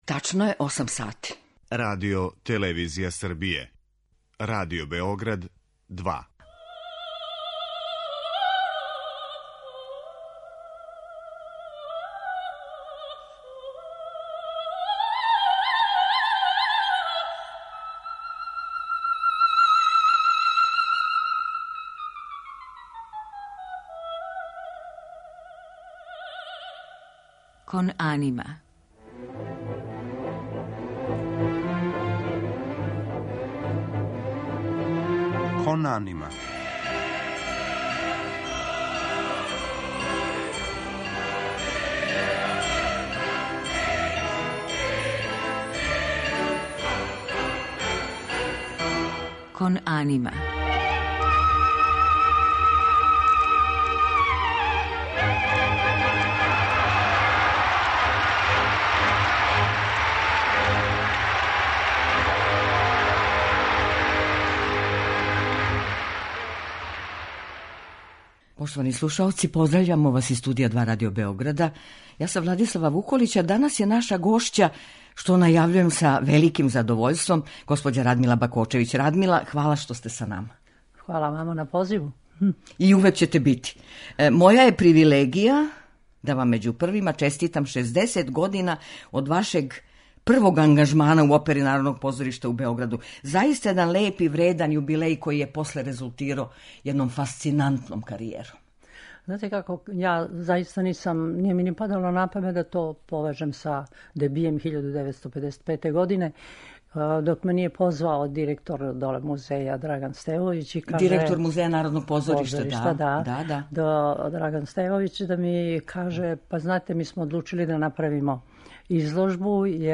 Гошћа данашње емисије је Радмила Бакочевић
Осим што ће говорити о својим улогама и сусретима са најпознатијим уметницима света са којима је наступала, осврнуће се и на недавно такмичење младих уметника "Никола Цвејић", које се сваке године одржава у Руми. У музичком делу емитоваћемо арије из опера Пучинија, Вердија, Коњовића и Белининија, у извођењу Радмиле Бакочевић.